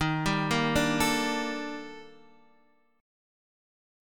D#M#11 chord